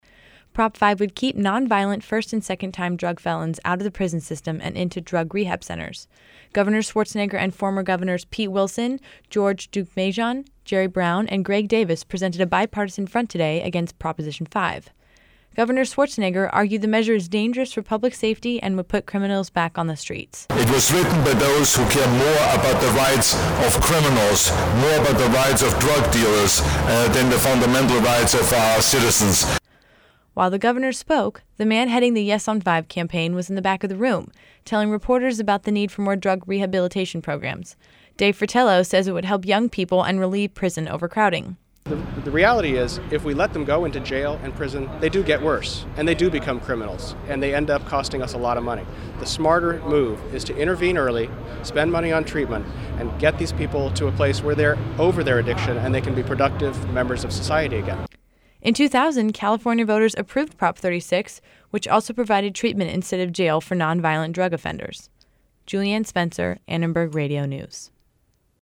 It was a historic day in downtown Los Angeles, as Governor Schwarzenegger was joined by four former governors to urge voters to vote no on Proposition Five. Promoters of Proposition Five also attended to voice their opinion.